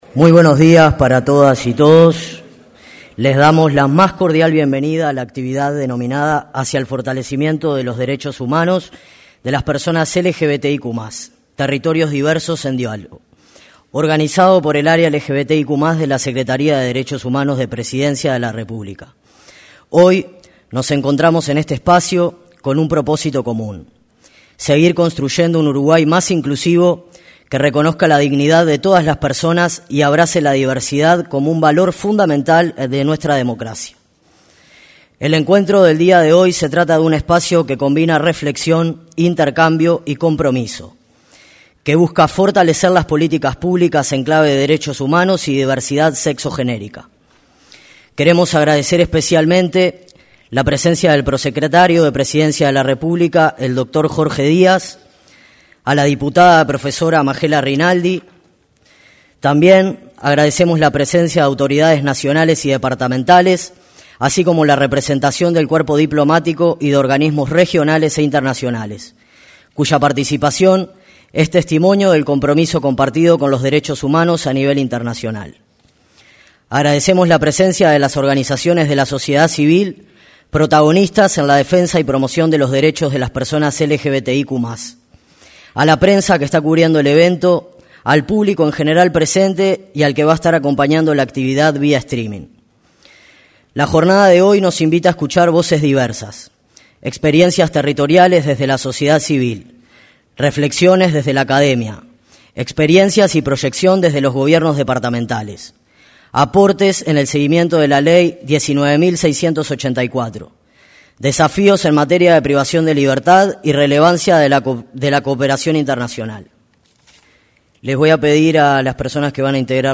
Conversatorio Hacia el Fortalecimiento de los Derechos Humanos de las Personas LGBTIQ+: Territorios Diversos
Conversatorio Hacia el Fortalecimiento de los Derechos Humanos de las Personas LGBTIQ+: Territorios Diversos 15/09/2025 Compartir Facebook X Copiar enlace WhatsApp LinkedIn En el marco del Mes de la Diversidad, la Secretaría de Derechos Humanos convocó a una jornada de diálogo, en la que participaron la titular de la referida dependencia, Collette Spinetti, la diputada María Inés Obaldía y el subsecretario de Desarrollo Social, Federico Graña.